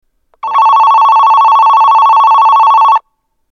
発車ベルは車掌がスイッチを押している間だけ流れます。
１番線発車ベル